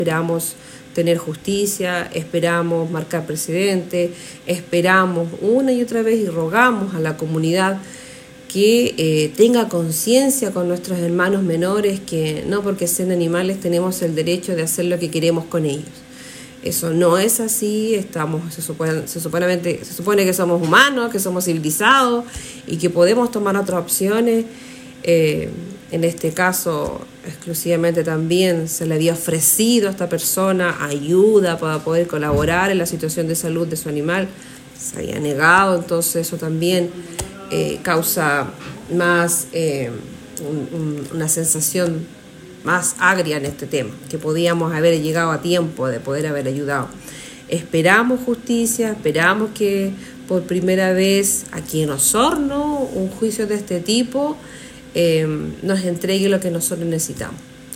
Esta situación fue consultada durante la última sesión del Concejo Municipal, para conocer los avances.
Por este motivo según declaró la concejala, esperan obtener buenas noticias de parte del Ministerio Público, a la vez que hizo un llamado para ser consciente en cuanto al cuidado de los animales de compañía.